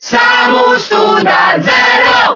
Category:Crowd cheers (SSBB) You cannot overwrite this file.
Zero_Suit_Samus_Cheer_Italian_SSBB.ogg